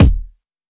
Dilla Kick 14.wav